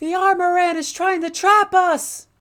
DRG-Femboy-Voice